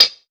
• Original Hihat Sound D Key 168.wav
Royality free pedal hi-hat sample tuned to the D note. Loudest frequency: 4531Hz
original-hihat-sound-d-key-168-pN3.wav